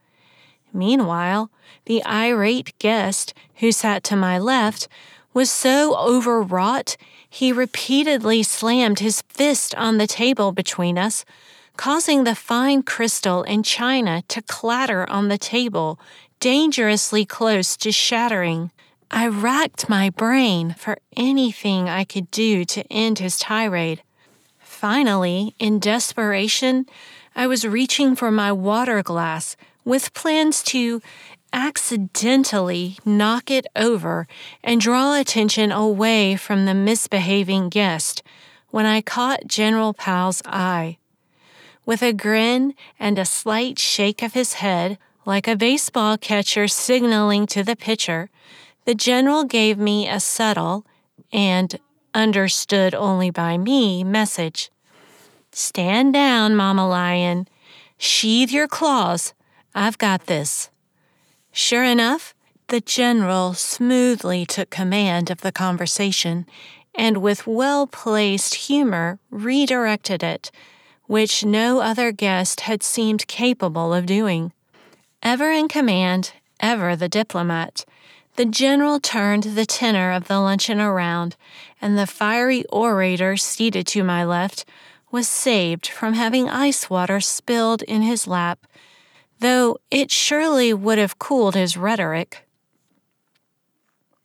A sunny, vivacious, confident voice that conveys professionalism and can tell a great story.
Audiobook Memoir Demo
American-General, American-Southern